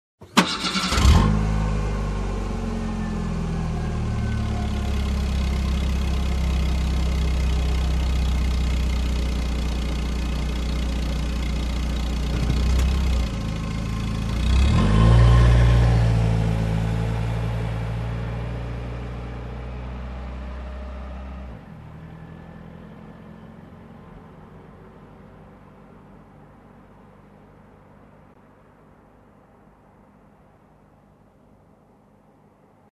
Звуки заводящейся машины - скачать и слушать онлайн бесплатно в mp3